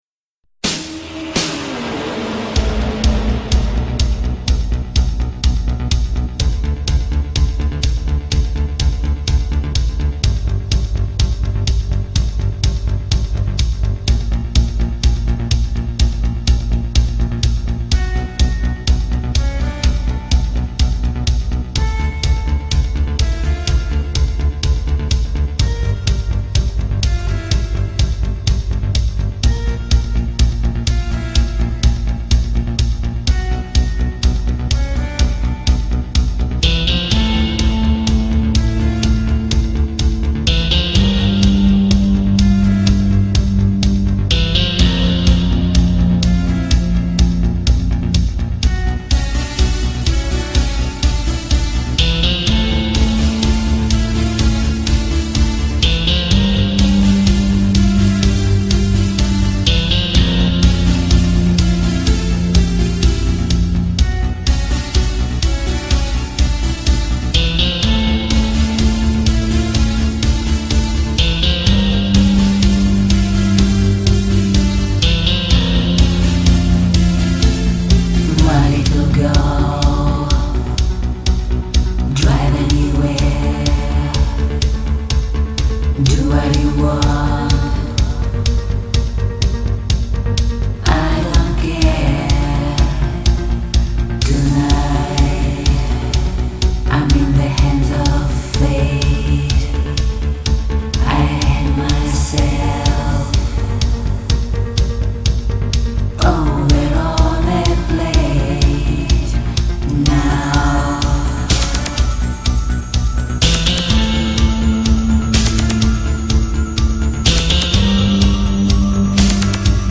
ни одна нота не дрогнула))) да
холодно, но невероятно привлекательно!